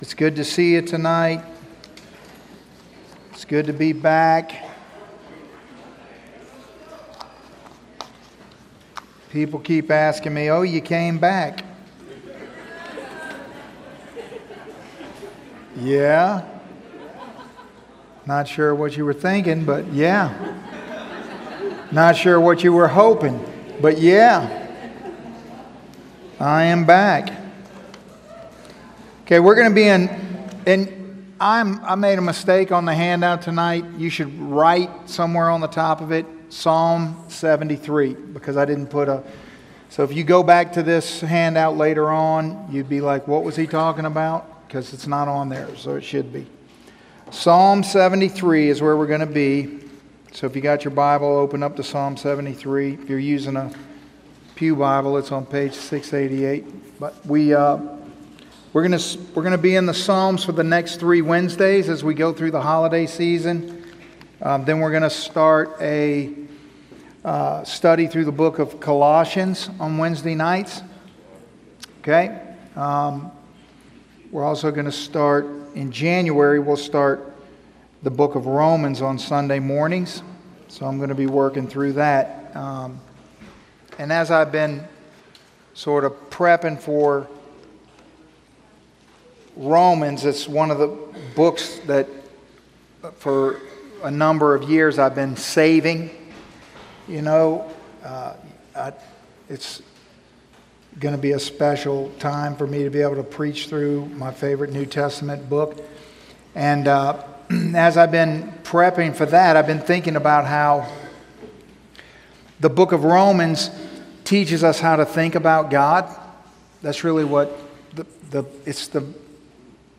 Series: Psalms - Wednesday Family Night Bible Study